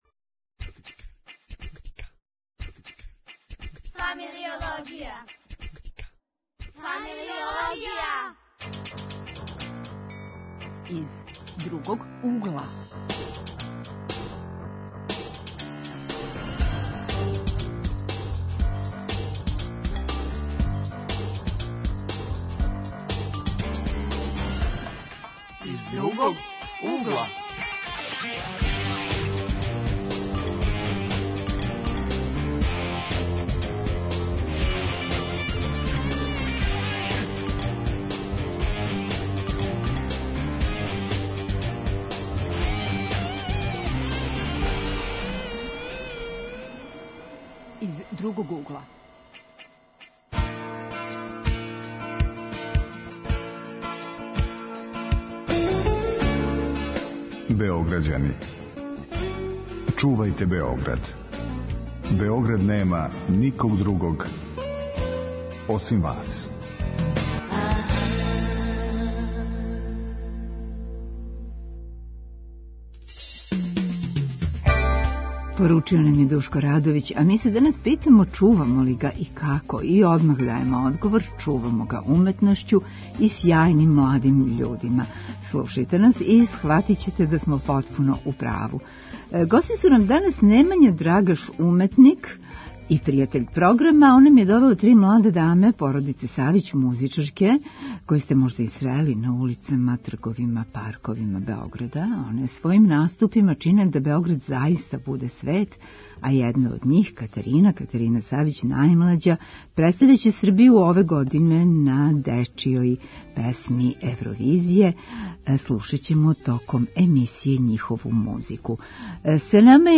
Гости су нам млади уметници, или - право лице Београда.